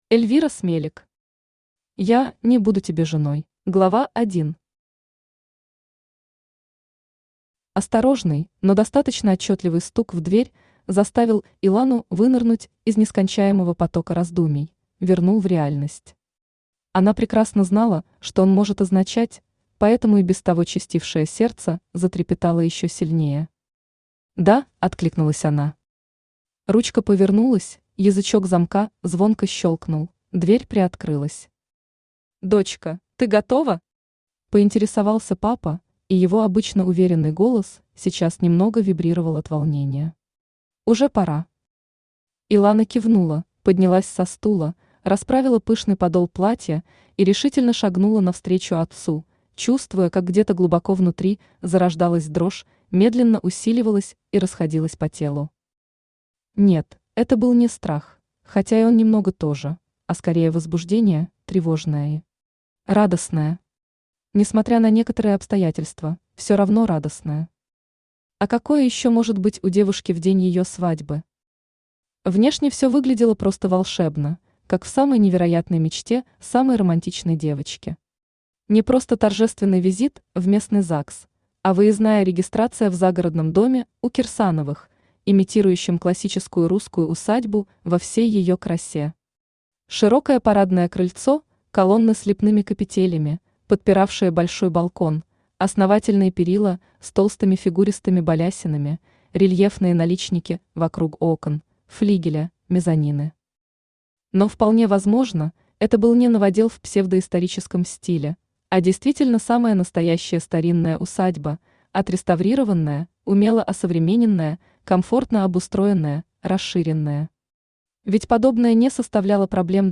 Аудиокнига Я (не) буду тебе женой | Библиотека аудиокниг
Aудиокнига Я (не) буду тебе женой Автор Эльвира Смелик Читает аудиокнигу Авточтец ЛитРес.